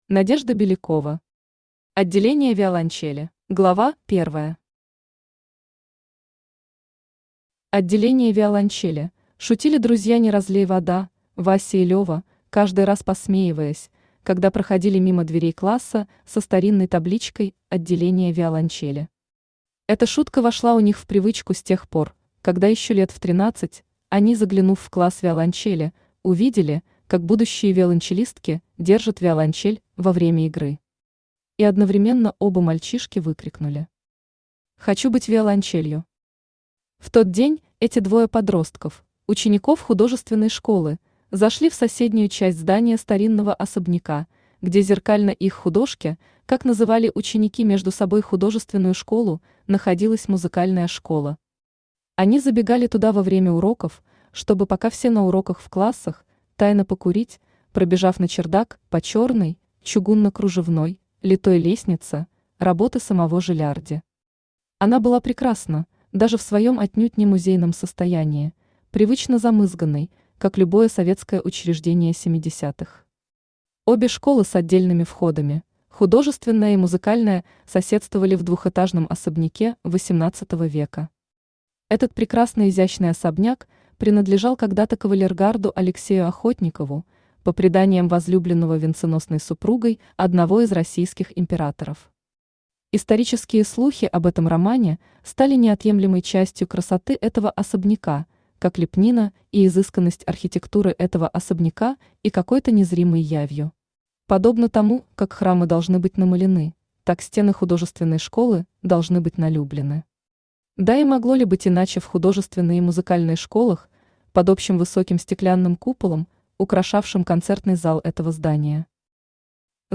Аудиокнига Отделение виолончели | Библиотека аудиокниг
Aудиокнига Отделение виолончели Автор Надежда Александровна Белякова Читает аудиокнигу Авточтец ЛитРес.